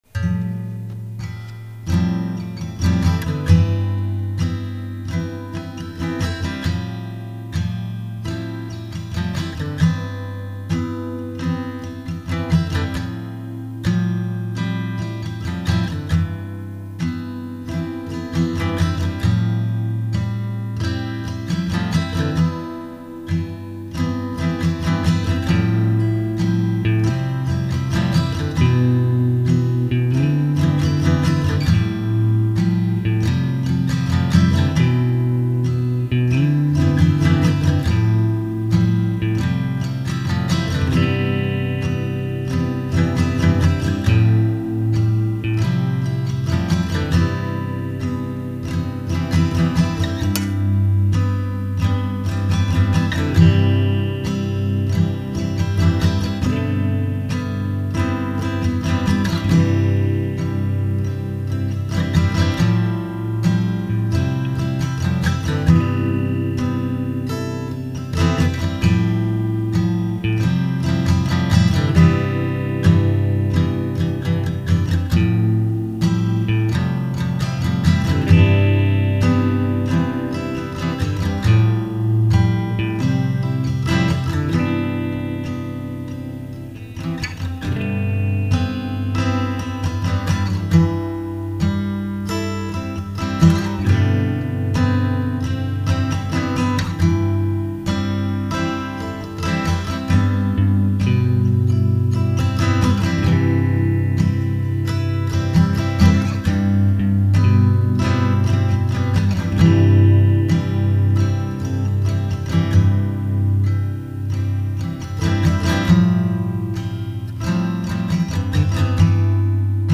handmade (old sketches, demos, errors)